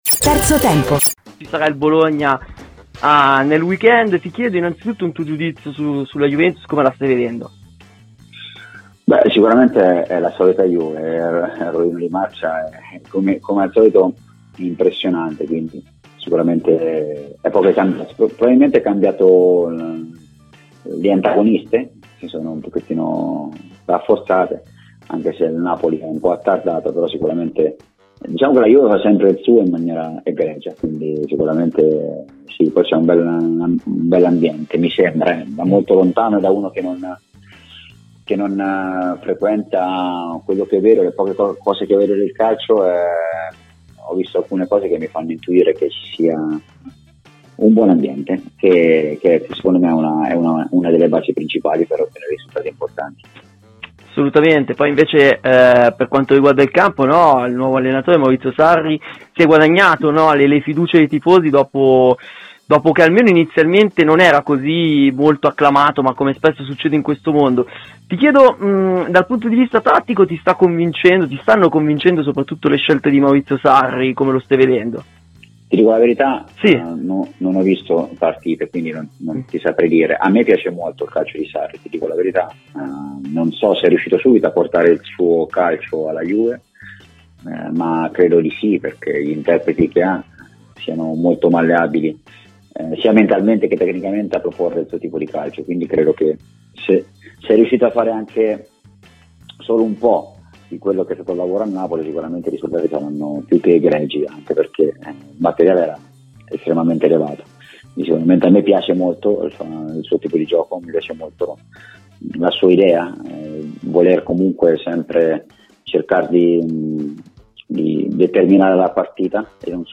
Carlo Tavecchio, ex presidente della FIGC, è intervenuto ai microfoni di Radio Bianconera, durante la trasmissione Avanti Madama: "La Turchia? Io non posso dare la posizione della Figc. La mia personale è che queste invasioni della politica nelle situazioni precarie in quell’area, non dovrebbero entrare nel caso. Si strumentalizza, ma non si devono fare i saluti militari in campo. La Uefa dovrà valutare l’accaduto, anche se ha le sue tempistiche e non è una cosa semplice, avendo già fissato le date e i luoghi delle partite. Molto difficile che la finale di Champions League venga spostata".